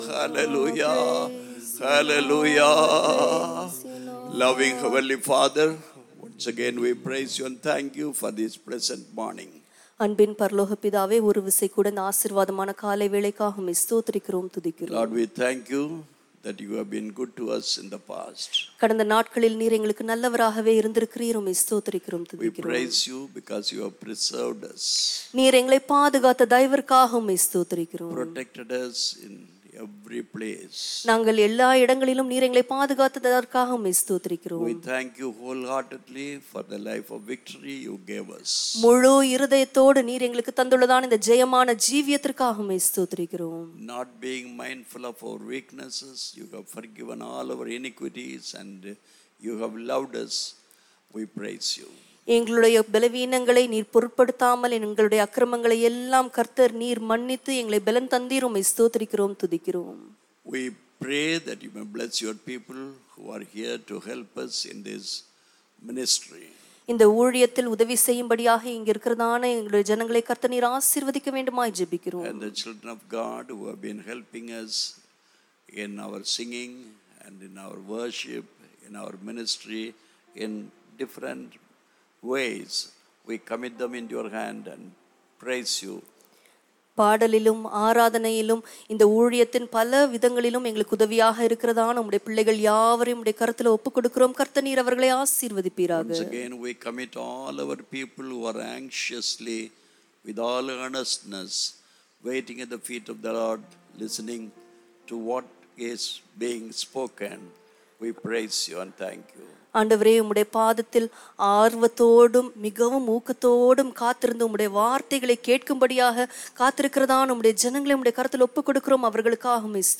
12 Sep 2021 Sunday Morning Service – Christ King Faith Mission
Service Type: Sunday Morning Service